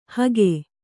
♪ hage